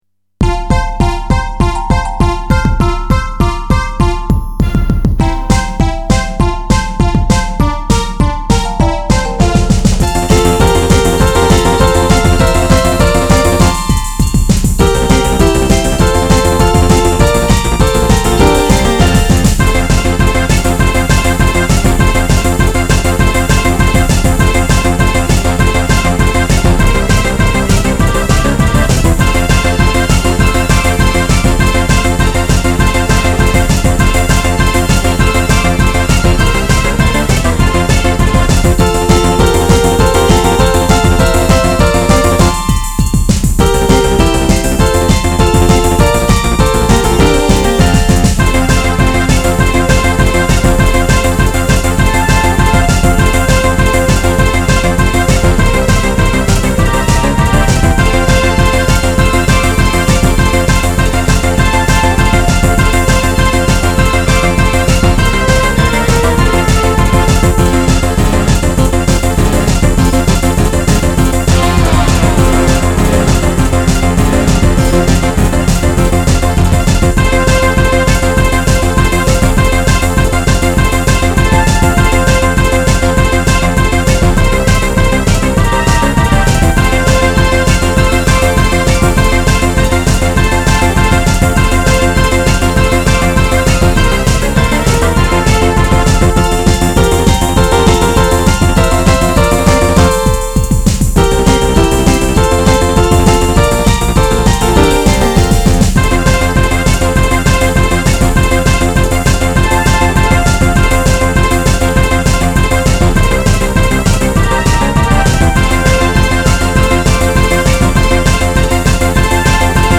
Nicht-Klassik
Hat so nen bisschen was von Zukunftsvision und Weltraumspiel